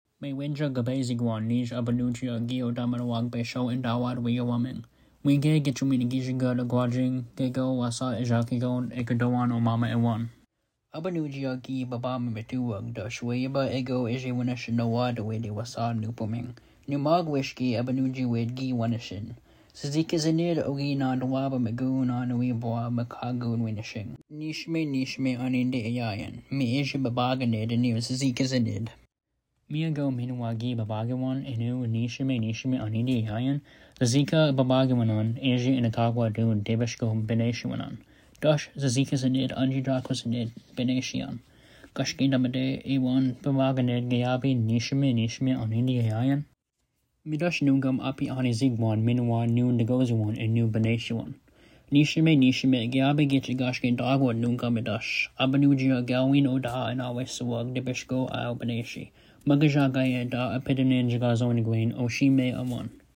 Nanaboozhoo-Nishiime-Story.mp3